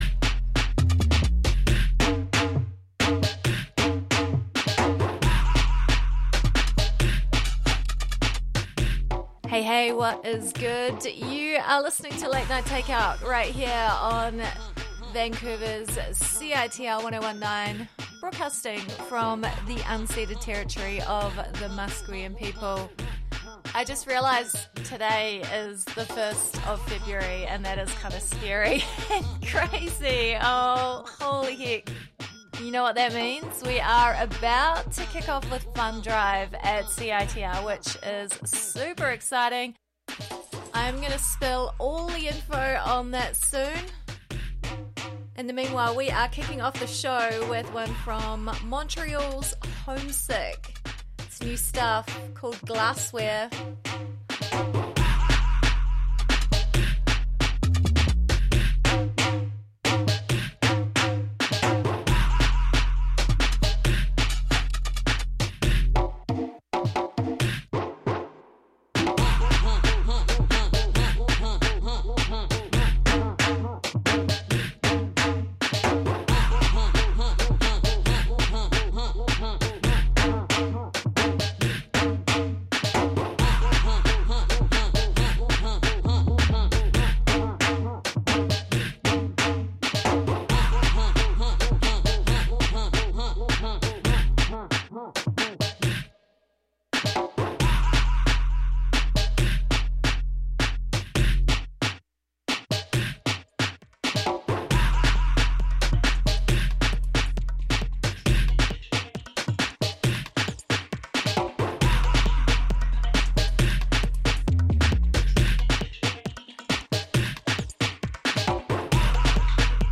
Droppin' some heat to keep you warm on this week's show!